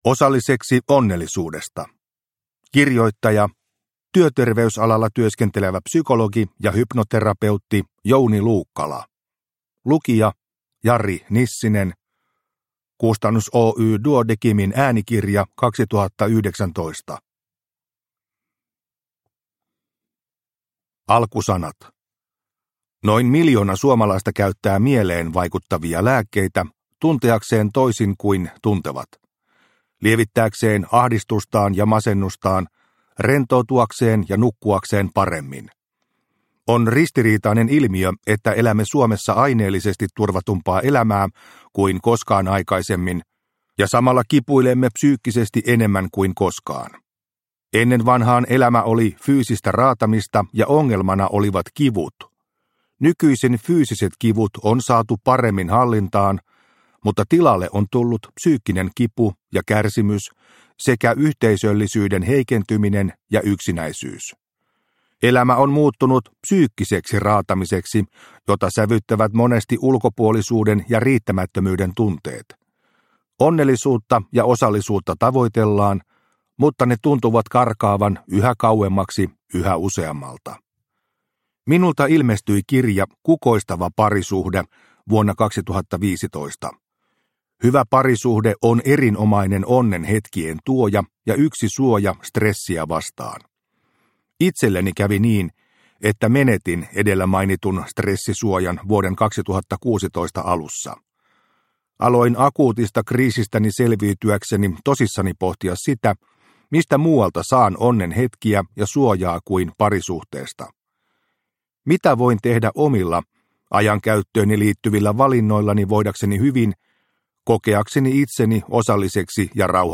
Osalliseksi onnellisuudesta – Ljudbok – Laddas ner